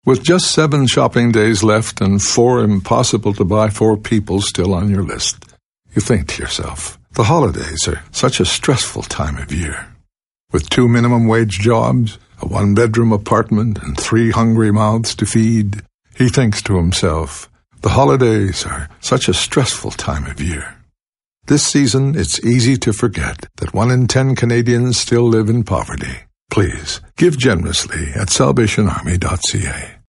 SilverPublic Service - Radio Campaign